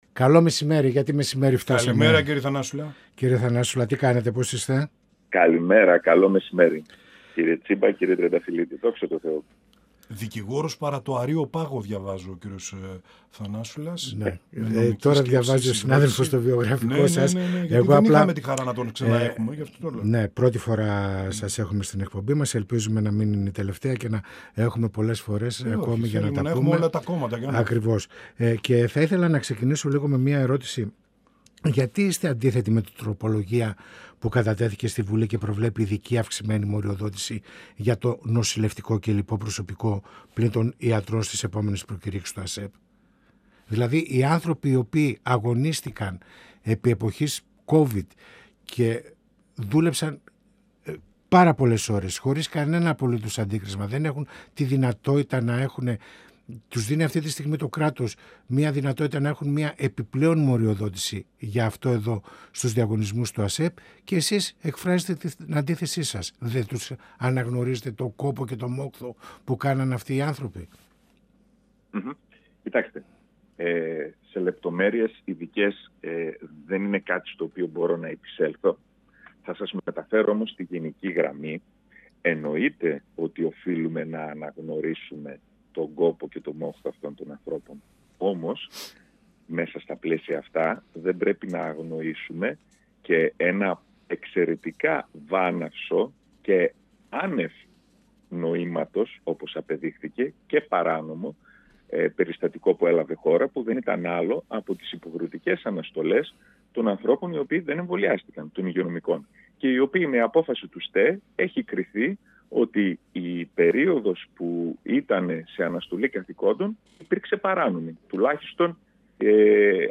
μιλώντας στην εκπομπή «Πανόραμα Επικαιρότητας» του 102FM της ΕΡΤ3.